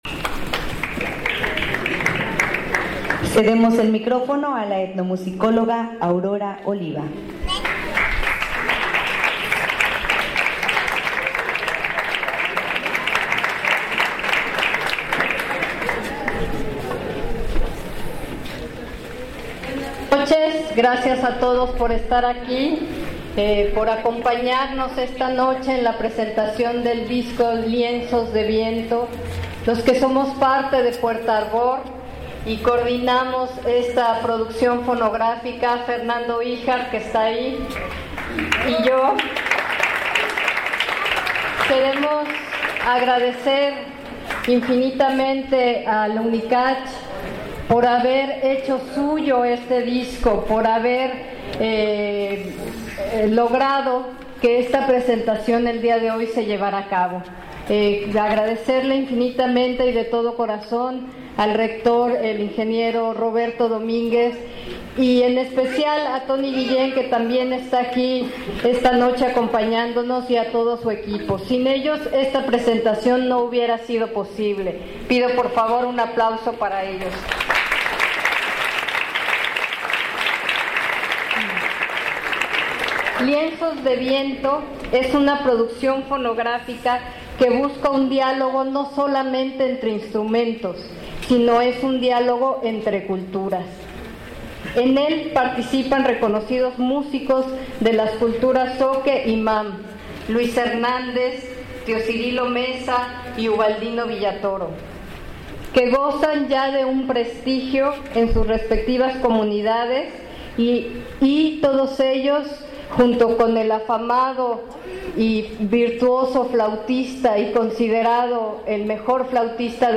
Lugar: Tuxtla Gutierrez, Chiapas; Mexico.